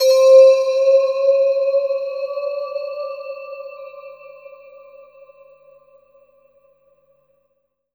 12SYNT01  -L.wav